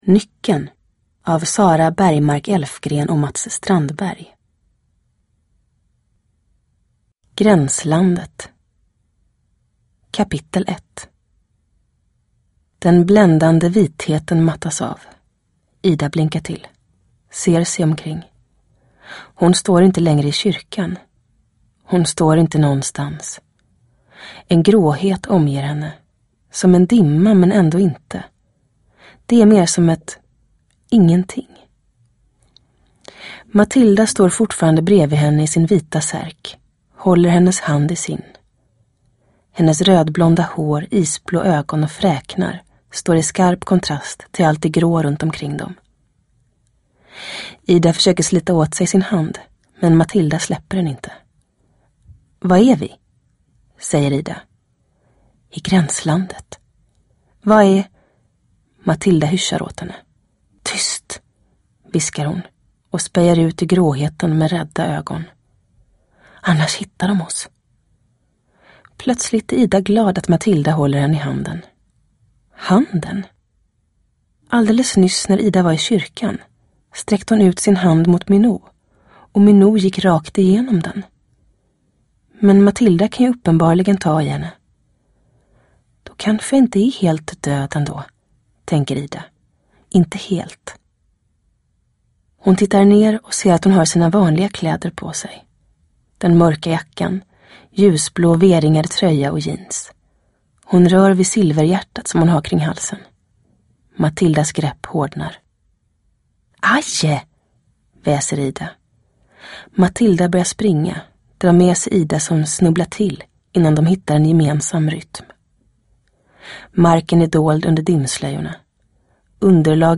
Uppläsare: Julia Dufvenius